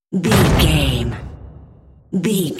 Cinematic drum hit trailer
Sound Effects
Atonal
heavy
intense
dark
aggressive